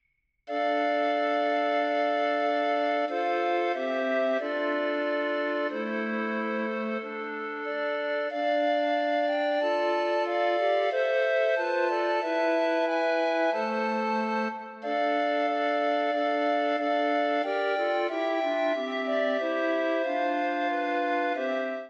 for ATTB recorders
Sandrin’s chanson, posted here, stands out for its emotional seriousness and introspective tone.